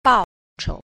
3. 報酬 – bàochóu – báo thù (đền đáp, thù lao)